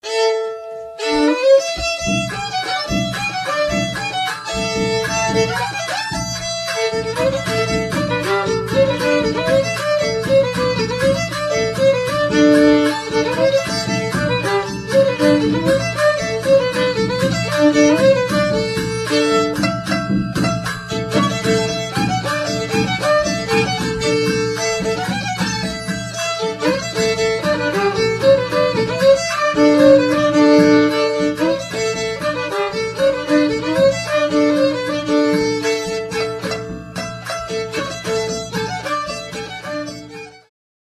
Owczarek
Badania terenowe
skrzypce
baraban